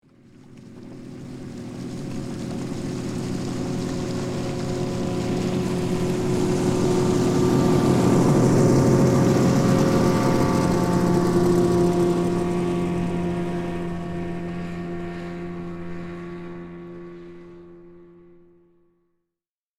Motorboat Passing By, Close Perspective 4 Sound Effect Download | Gfx Sounds
Motorboat-passing-by-close-perspective-4.mp3